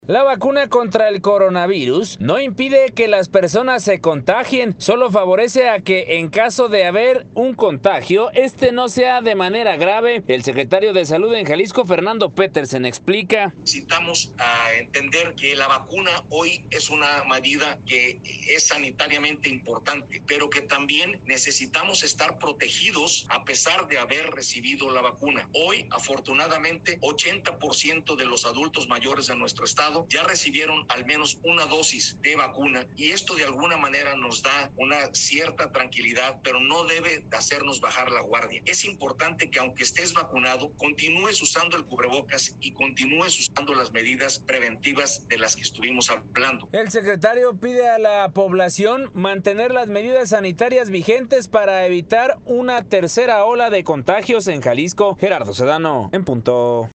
La vacuna contra el coronavirus no impide que las personas se contagien, sólo favorece a que en caso de haber un contagio, éste no sea de manera grave. El secretario de salud en Jalisco, Fernando Petersen explica: